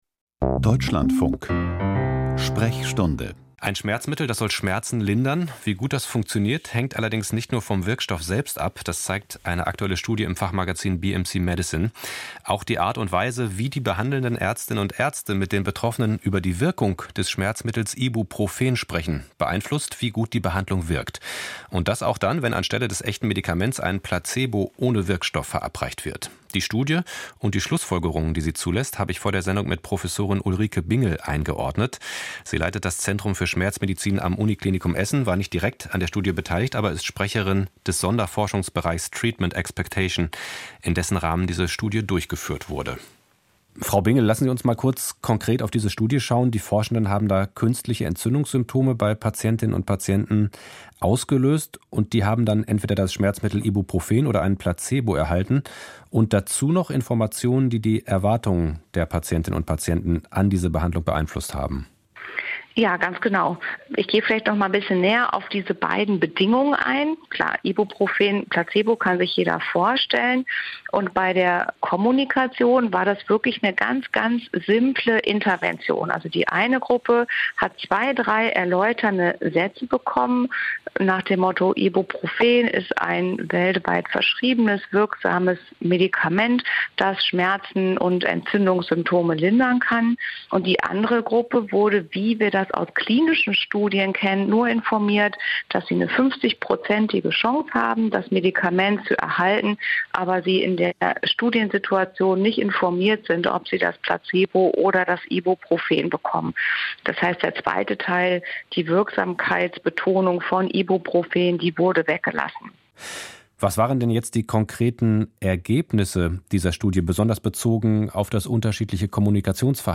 Erwartungshaltung beeinflusst Wirkung von Schmerzmittel: Interview